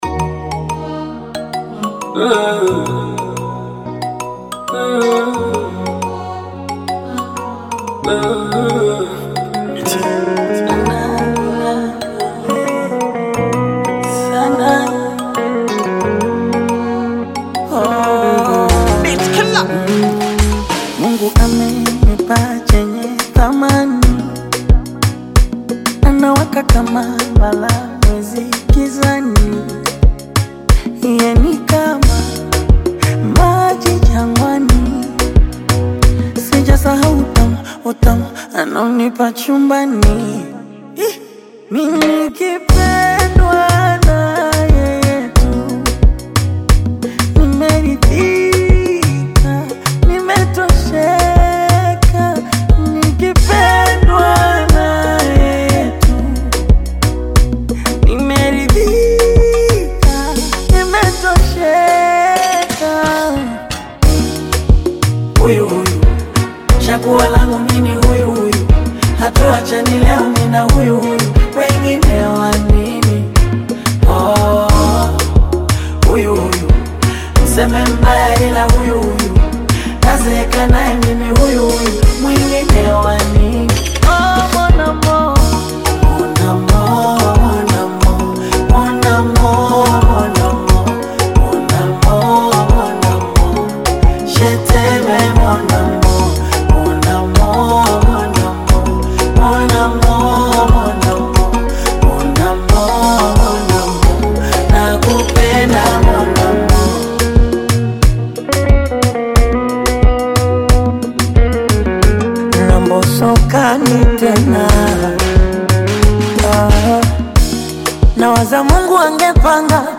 romantic Bongo Flava/Afro-Pop collaboration